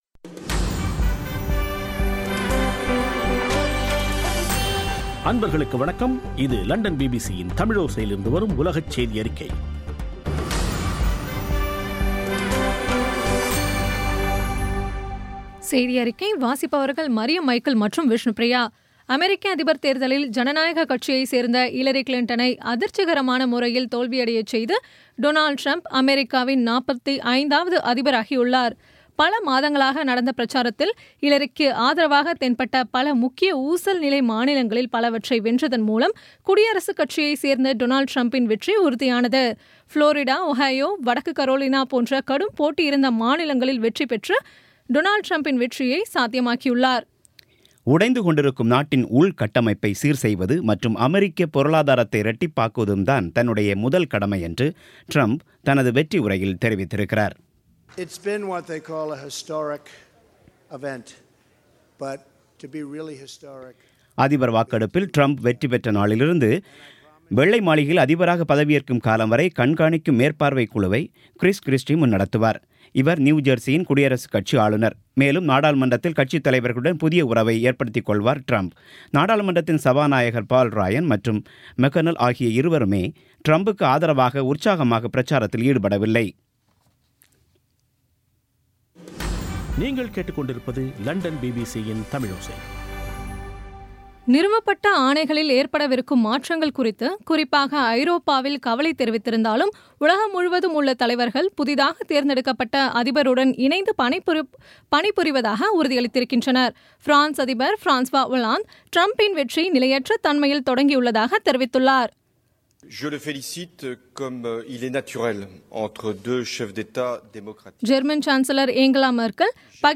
இன்றைய (நவம்பர் 9ம் தேதி ) பிபிசி தமிழோசை செய்தியறிக்கை